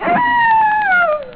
WOLF_2.wav